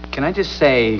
Here you will find various sounds taken from Buffy, the Vampire Slayer.